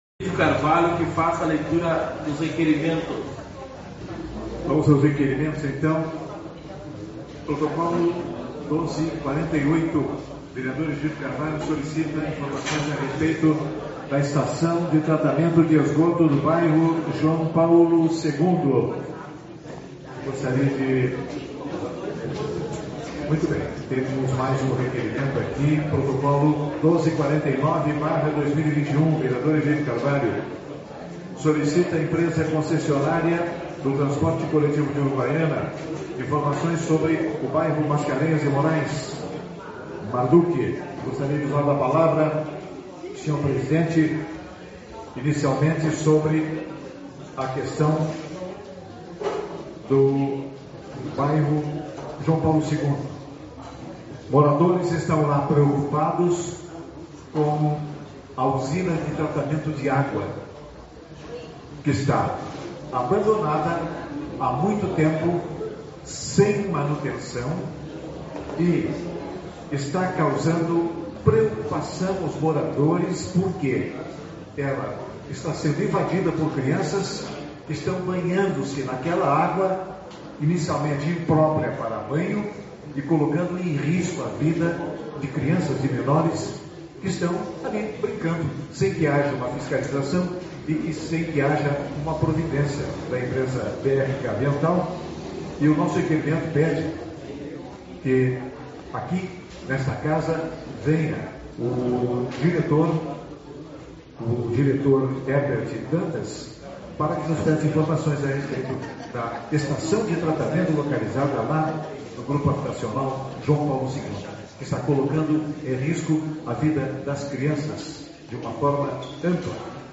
16/12 - Reunião Ordinária